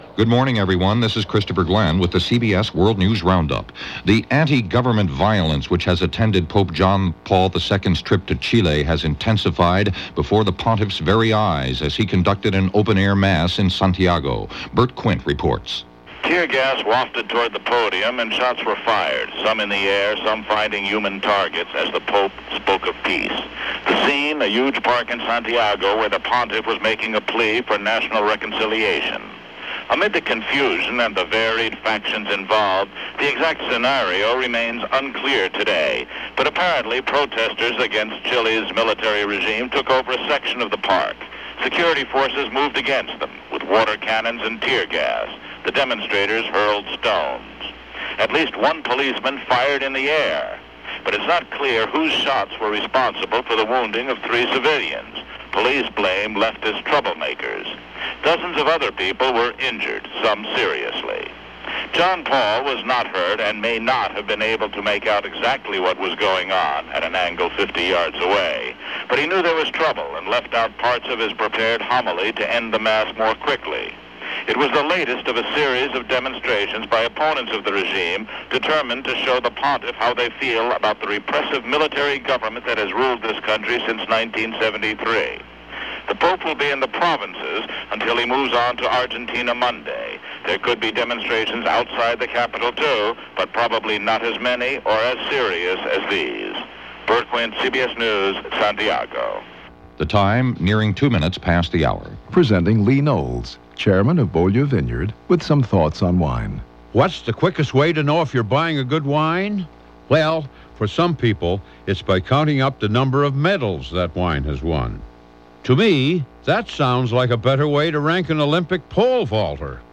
And that’s a small slice of what happened, this April 4, 1987 as reported by The CBS World News Roundup.